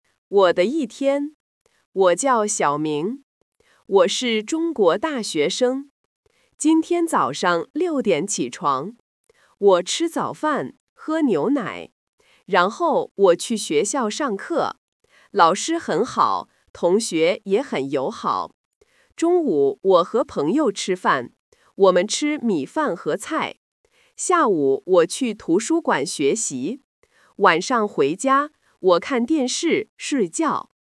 Challenge yourself by listening directly at the normal speed.
《我的一天》Normal-1.mp3